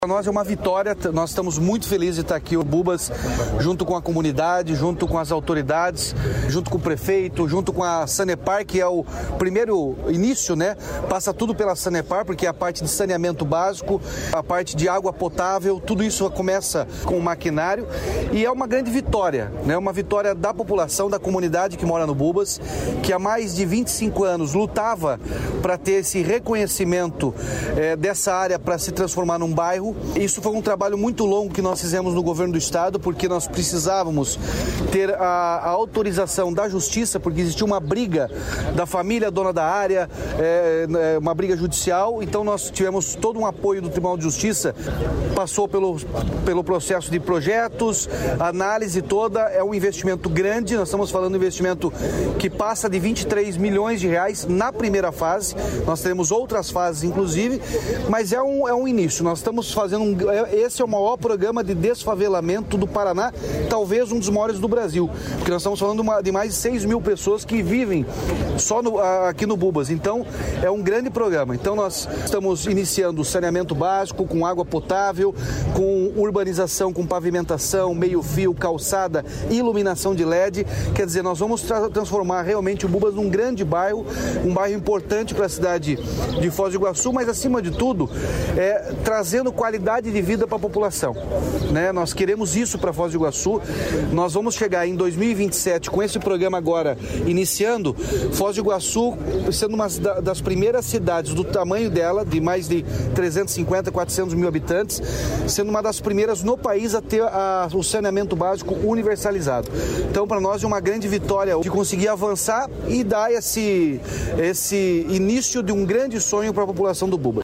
Sonora do governador Ratinho Junior sobre as obras de urbanização no Bubas, em Foz do Iguaçu